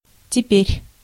Ääntäminen
IPA : [naʊ]